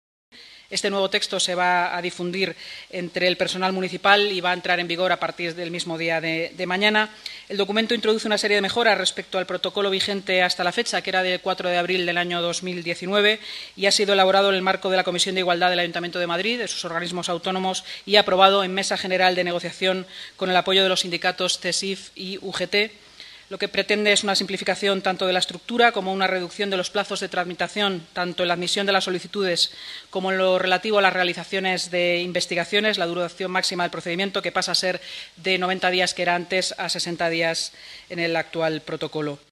Nueva ventana:Inmaculada Sanz, vicealcaldesa y portavoz municipal